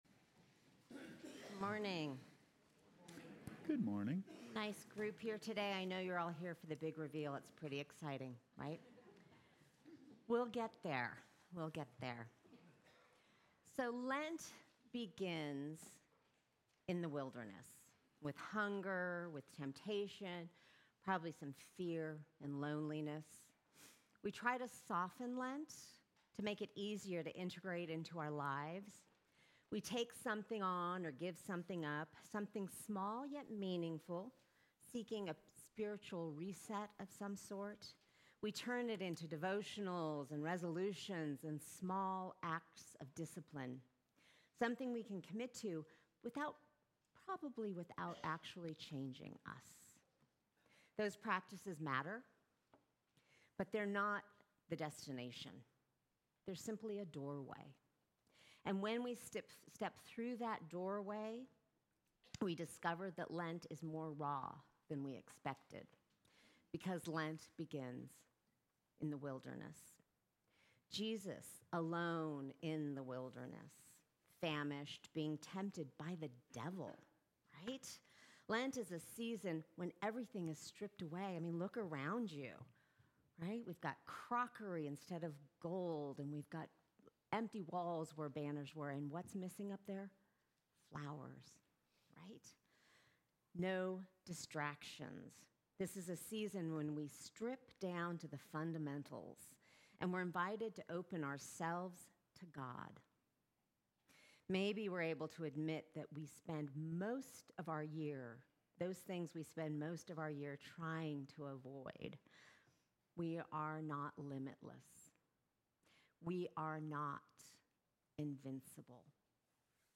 Sermons from St. John's Episcopal Church in Jackson Hole, WY.
Sermons from St. John's Episcopal Church